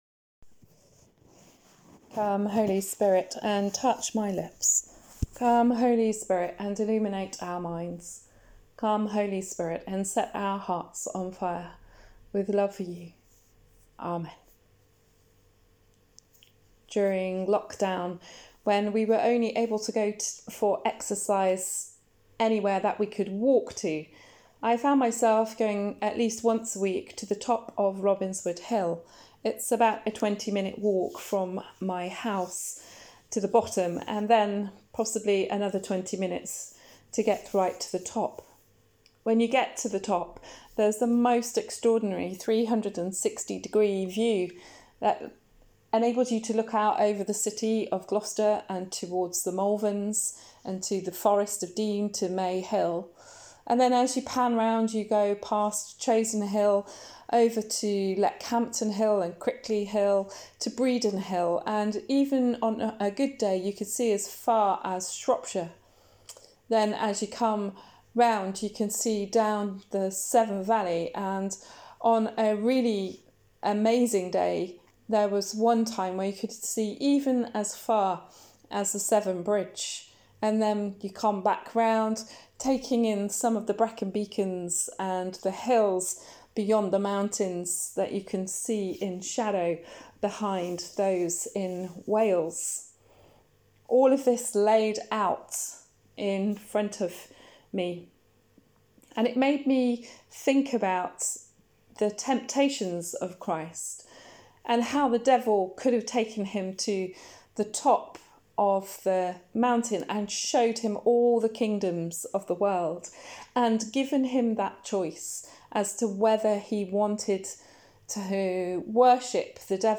Sermon: Is There Anyone Up There?